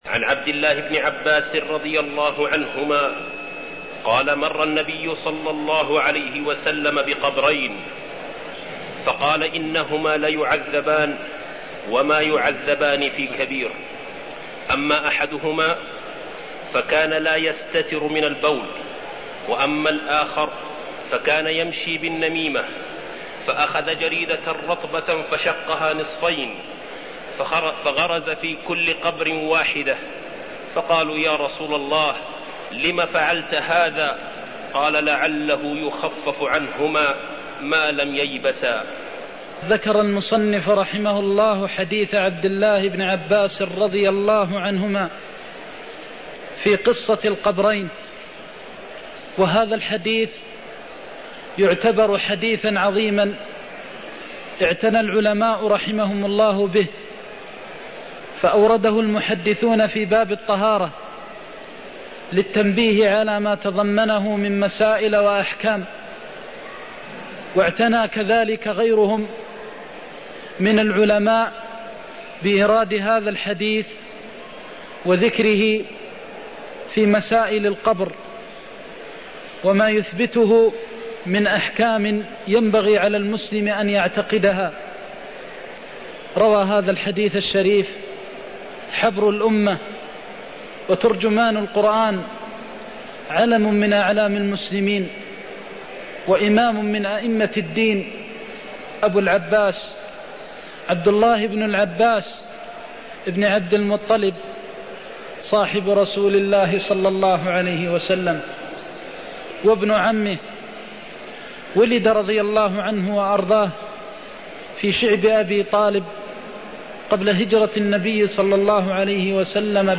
المكان: المسجد النبوي الشيخ: فضيلة الشيخ د. محمد بن محمد المختار فضيلة الشيخ د. محمد بن محمد المختار إنهما ليعذبان وما يعذبان في كبير (17)